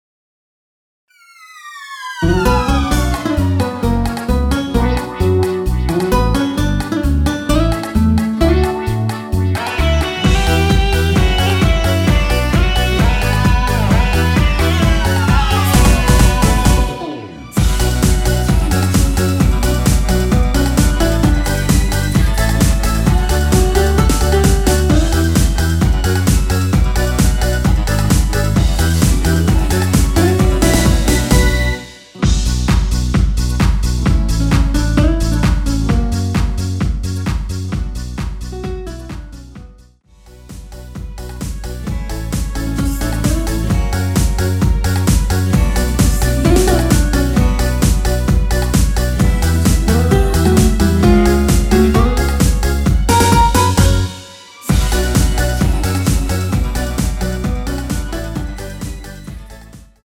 원키에서(-2)내린 코러스 포함된 MR입니다.
Bm
앞부분30초, 뒷부분30초씩 편집해서 올려 드리고 있습니다.
중간에 음이 끈어지고 다시 나오는 이유는